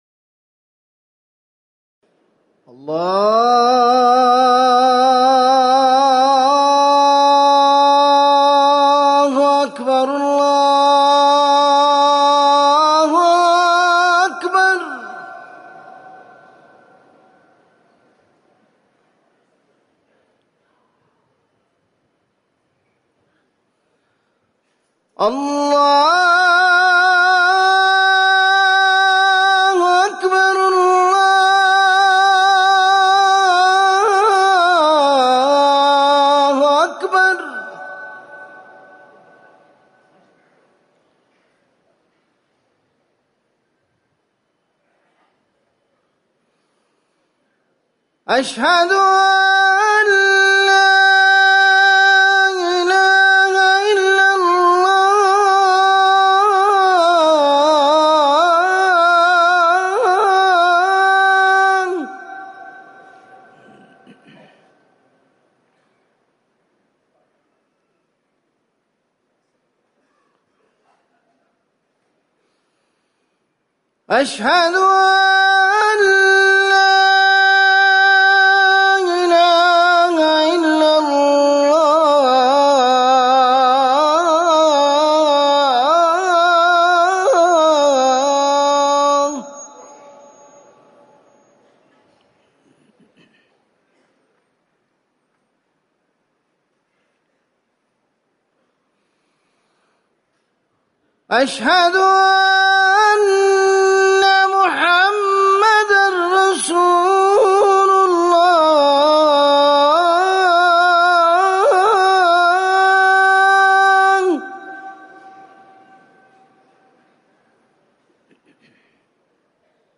أذان العشاء - الموقع الرسمي لرئاسة الشؤون الدينية بالمسجد النبوي والمسجد الحرام
تاريخ النشر ٢٣ صفر ١٤٤١ هـ المكان: المسجد النبوي الشيخ